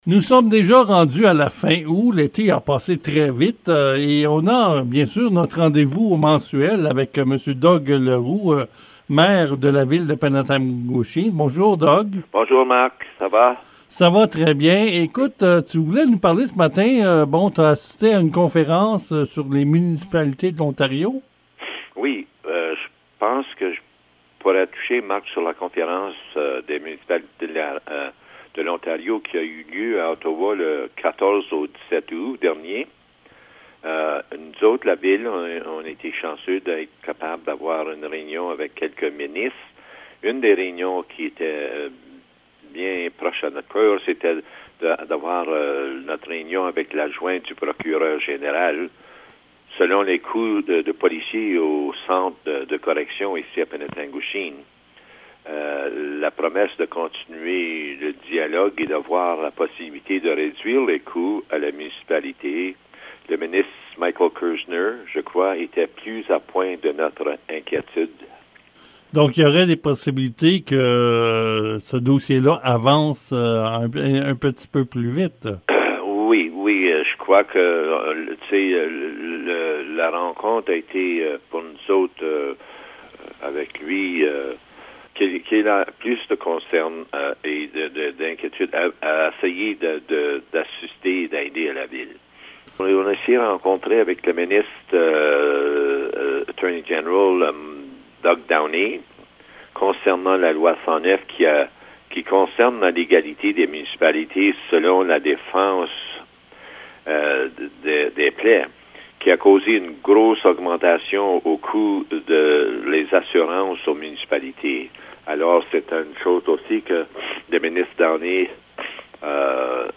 Le Maire de Penetanguishene est aujourd'hui venu nous parler de la conférence des municipalités de l'Ontario. Il a aussi été question de la reconnaissance de la ville, envers certains citoyens.
Rendez-vous mensuel avec le maire de Penetanguishene Doug Leroux: